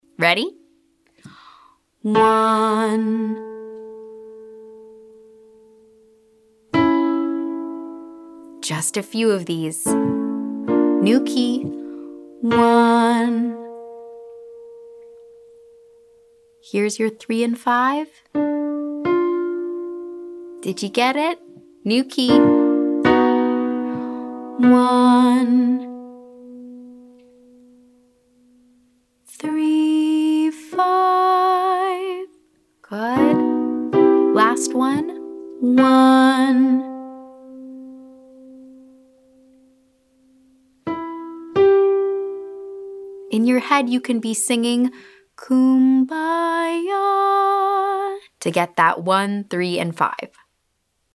Exercise/game: Play 1, student sing 135